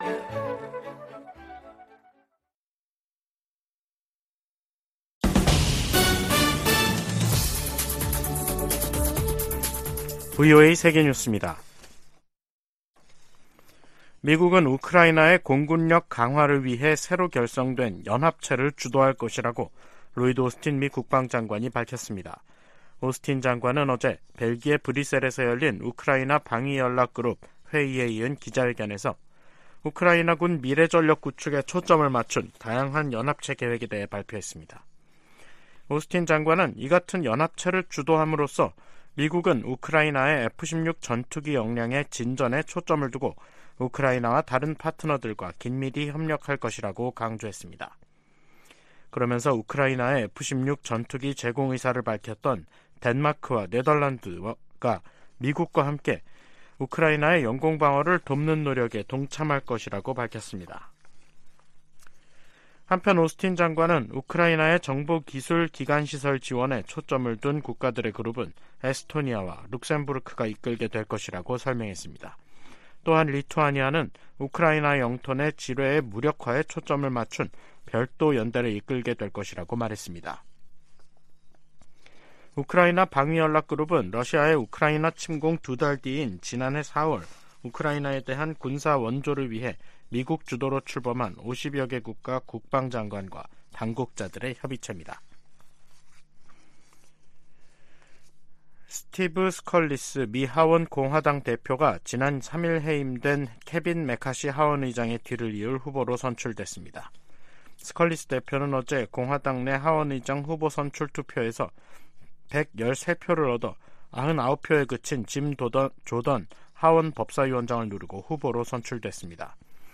VOA 한국어 간판 뉴스 프로그램 '뉴스 투데이', 2023년 10월 12일 2부 방송입니다. 백악관이 이스라엘 지상군의 가자지구 투입에 대비해 민간인 대피 대책을 논의 중이라고 밝혔습니다. 한국을 방문한 미국 상원의원들이 윤석열 한국 대통령과 만나 대북 상호 방위에 대한 초당적 지지를 재확인했습니다. 9.19 남북군사합의가 팔레스타인 무장 정파 하마스식의 북한 기습 도발에 대한 감시·정찰 능력을 제한한다고 미국 전문가들이 지적했습니다.